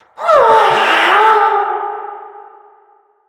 balloon_ghost_wail_02.ogg